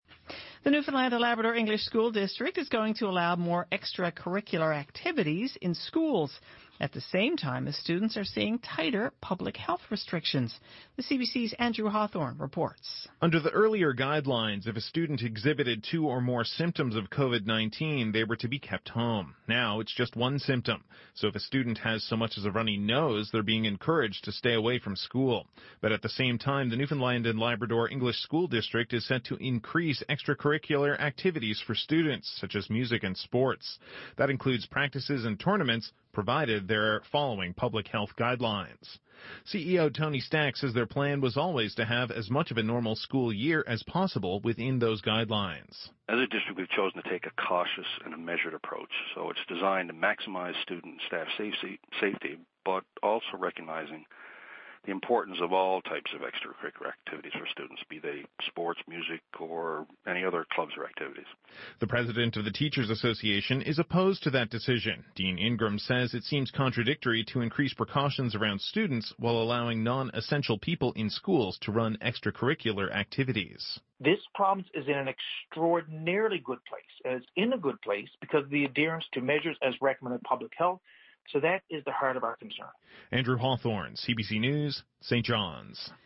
Media Interview - CBC 5pm News Nov 13, 2020